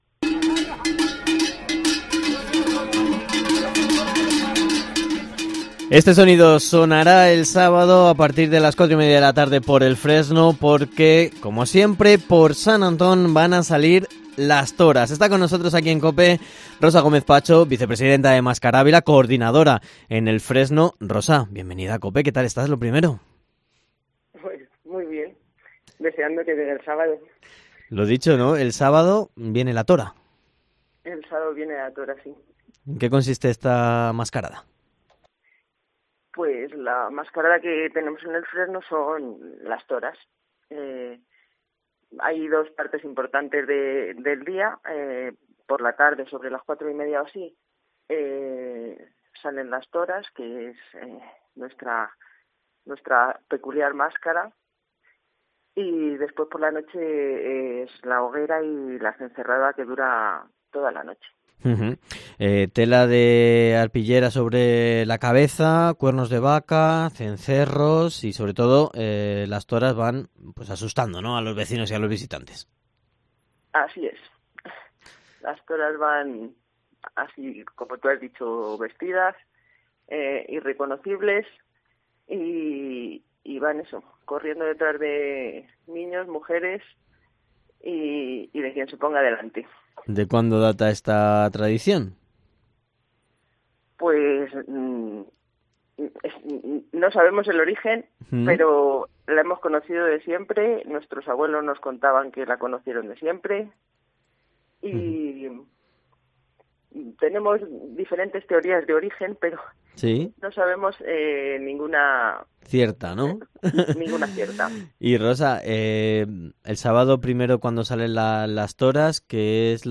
Entrevista / Las Toras recorrerán las calles de El Fresno este sábado -19-enero